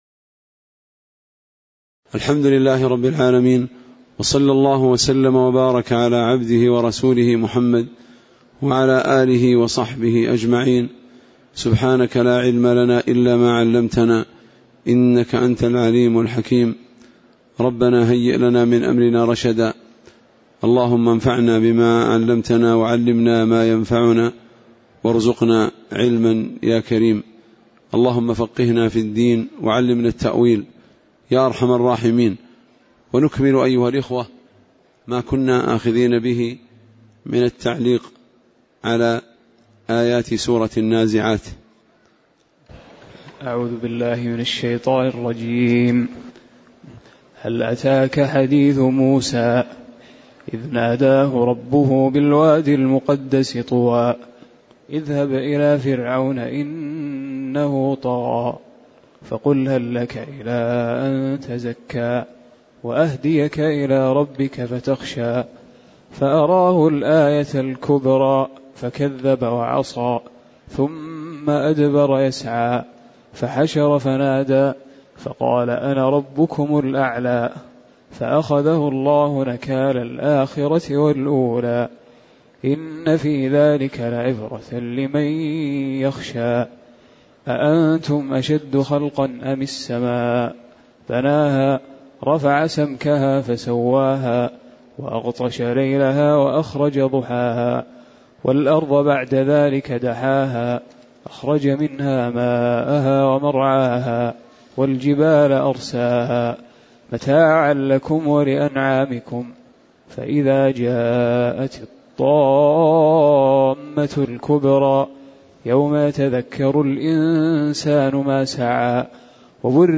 تاريخ النشر ١٣ شوال ١٤٣٨ هـ المكان: المسجد النبوي الشيخ